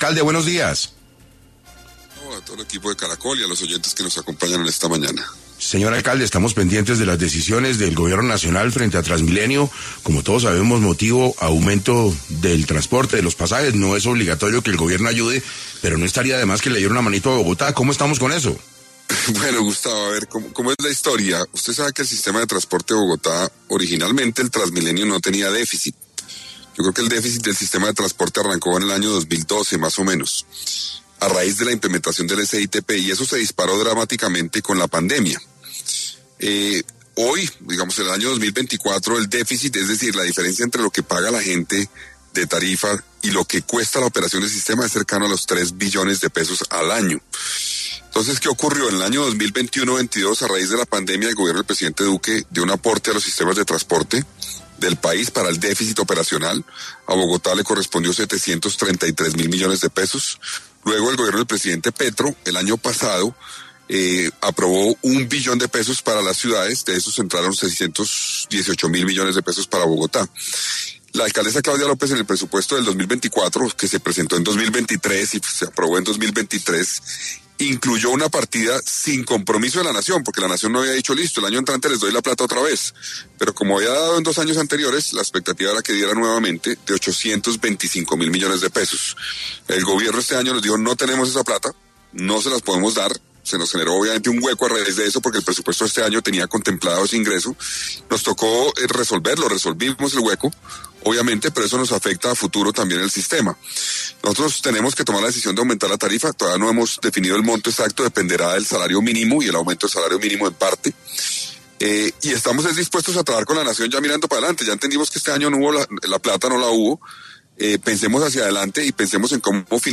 En 6AM de Caracol Radio estuvo Carlos Fernando Galán, alcalde de Bogotá, quien habló sobre qué le responde al presidente Gustavo Petro de los recursos del Gobierno para la operación del Transmilenio.